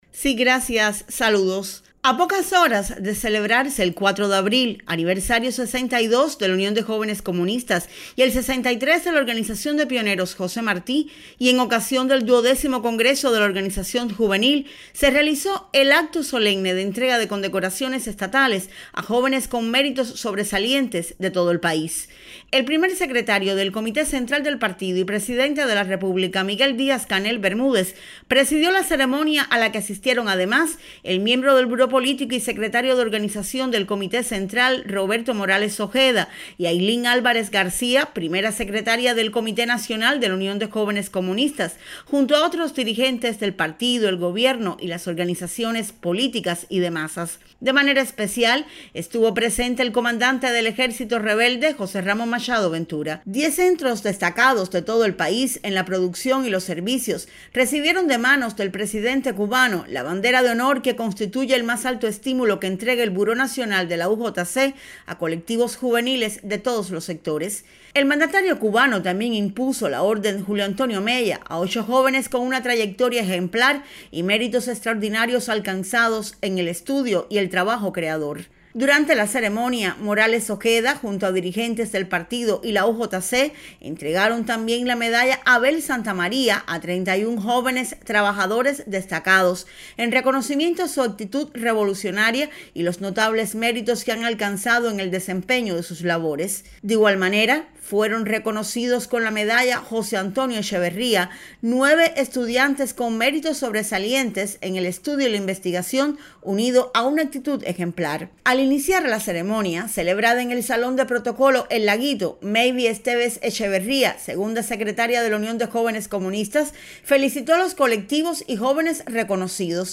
Este martes en la tarde tuvo lugar en el capitalino Salón de Protocolo del Laguito y con la presencia del Presidente de la República de Cuba, Miguel Díaz-Canel Bermúdez, el Acto Solemne de entrega de condecoraciones estatales a jóvenes con méritos sobresalientes.